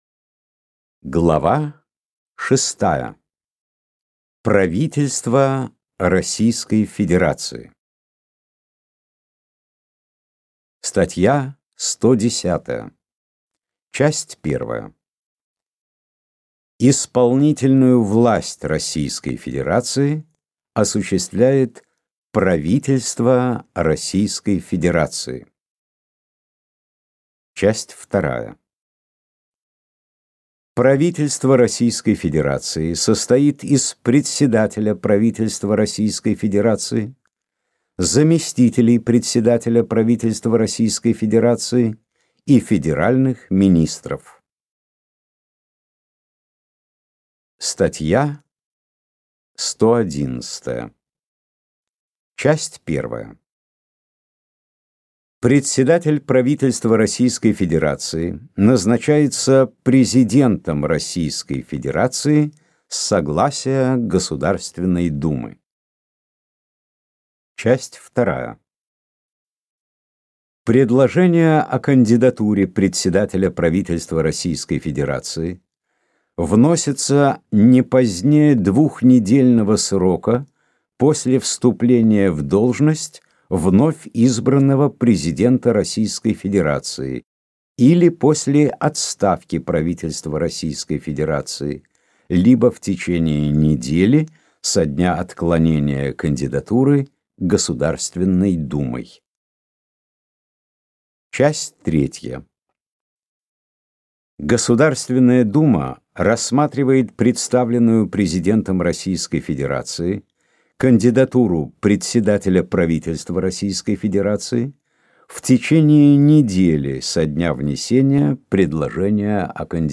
Аудиокнига Конституция Российской Федерации | Библиотека аудиокниг